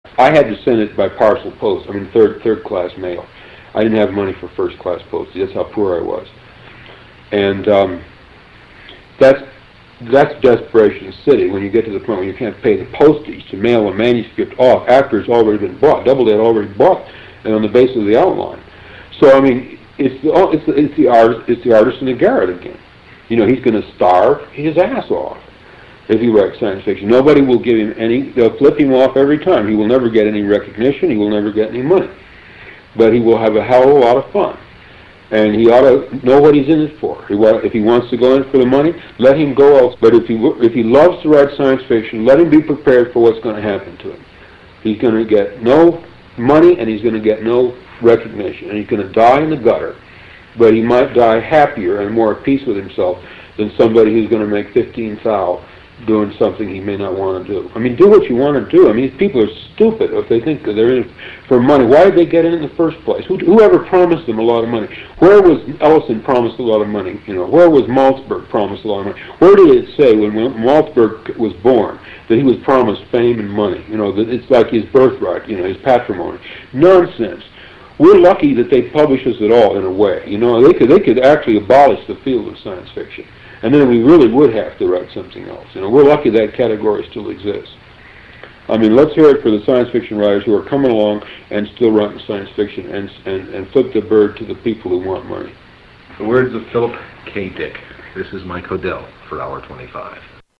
Interview with Philip K Dick 11